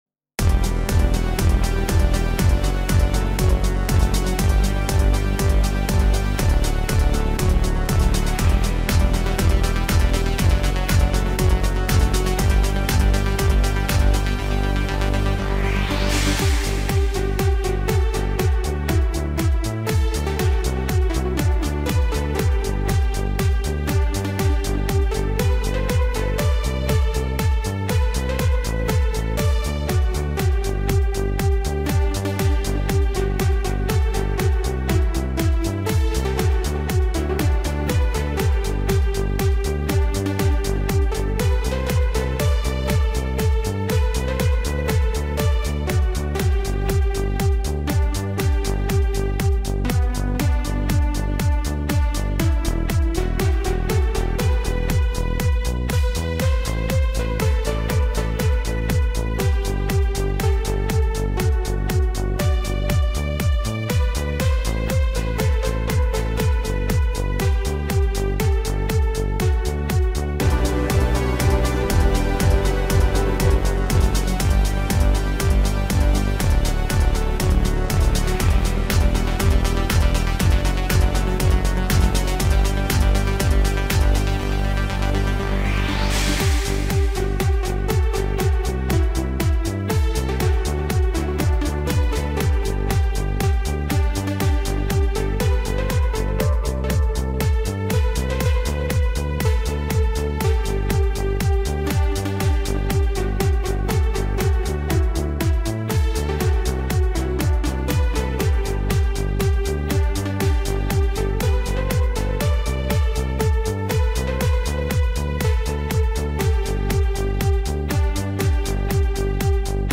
REMİX G MAJOR KARAOKE